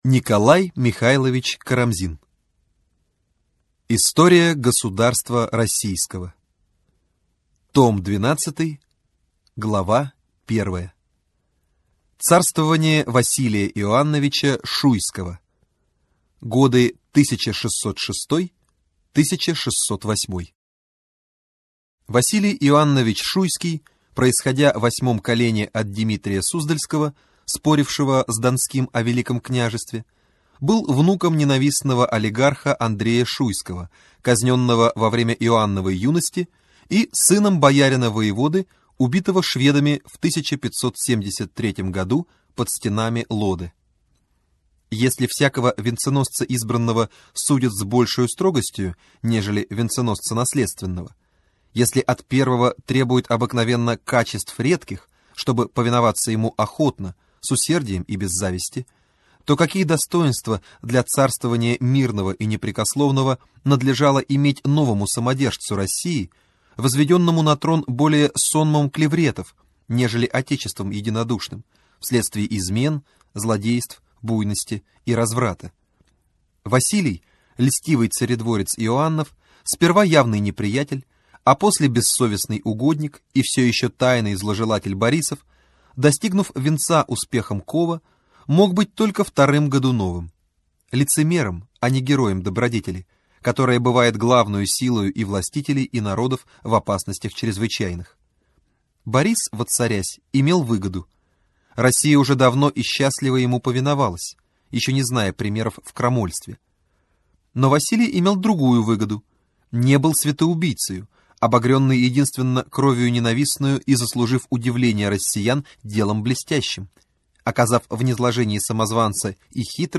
Аудиокнига История государства Российского. Том 12. От Василия Шуйского до Междуцарствия. 1606-1612 гг.